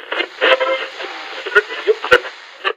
radio_mixdown_4.ogg